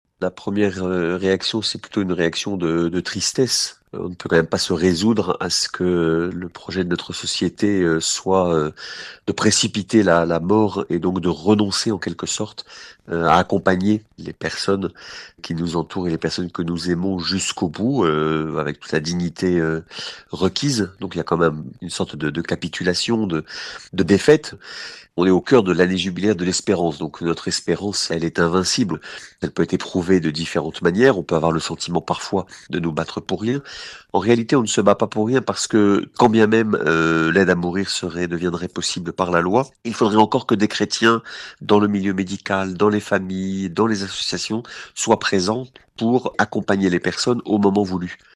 au micro de RCF Sarthe, après le vote des députés.